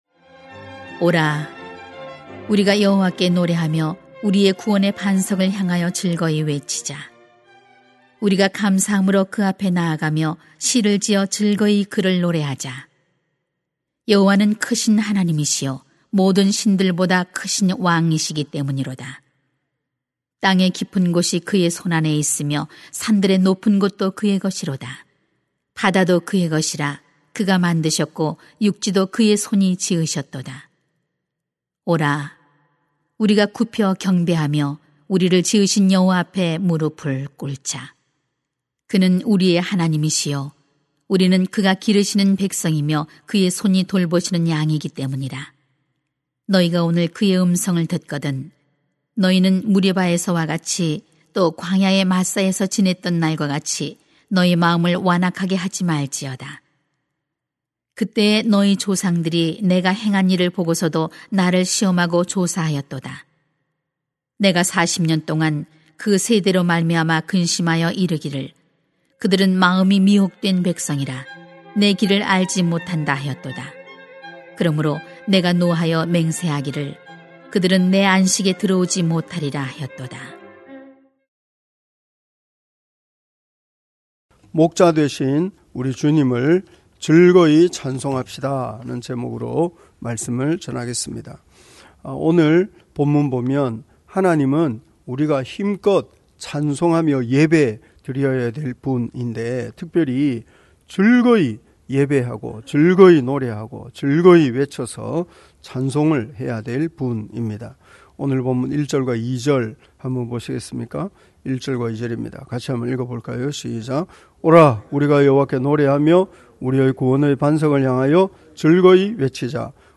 [시 95:1-11] 목자되신 우리 주님을 즐거이 찬송합시다 > 새벽기도회 | 전주제자교회